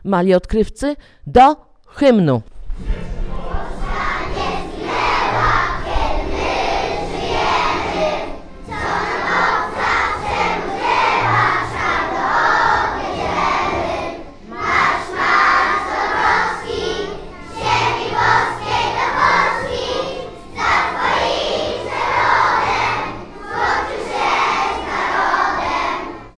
Gdy wybiła godzina 11:11, nastąpiło oficjalne odśpiewanie hymnu. Cztery zwrotki śpiewało blisko 200 przedszkolaków